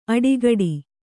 ♪ aḍigaḍige